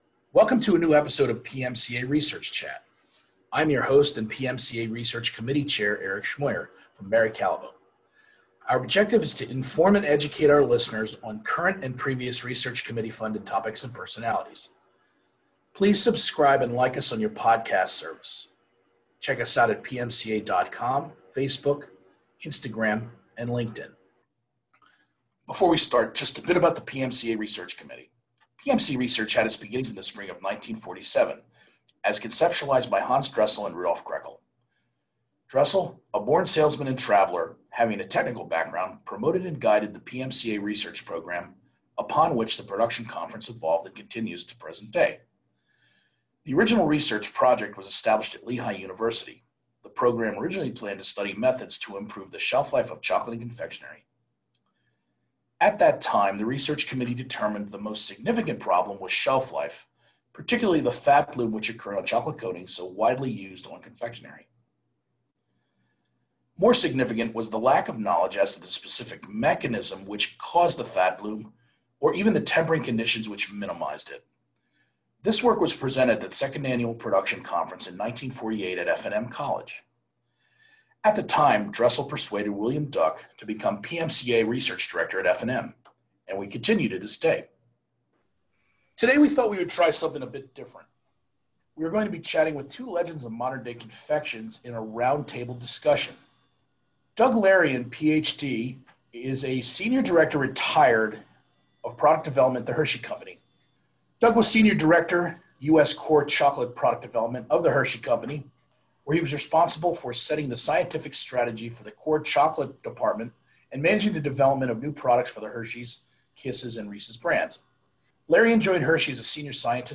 Episode 15: Research Chair Roundtable